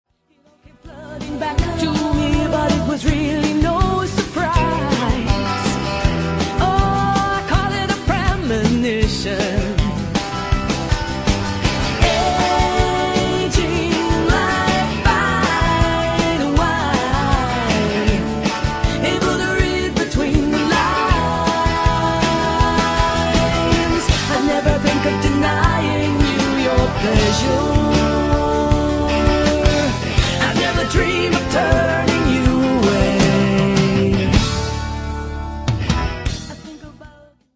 original pop music
from folk and pop to jazz influences.